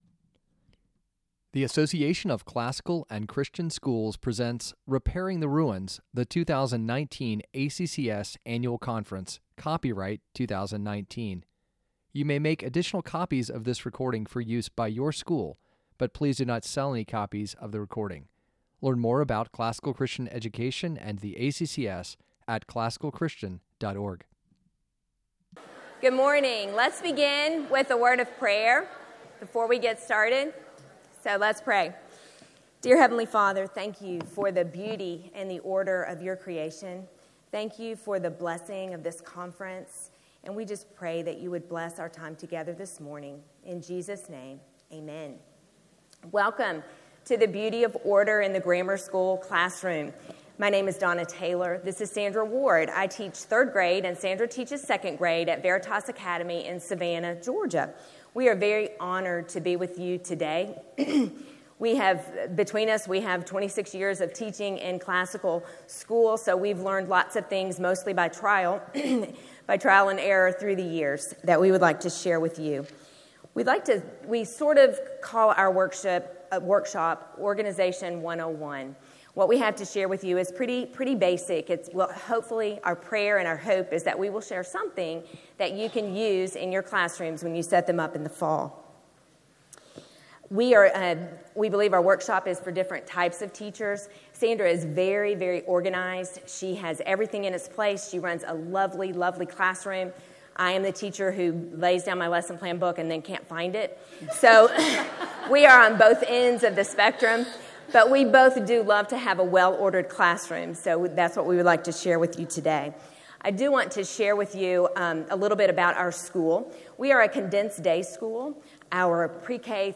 2019 Workshop Talk | 48:14 | K-6, Teacher & Classroom, General Classroom